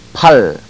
To hear proper pronunciation, click one of the links below
Pahl - Arm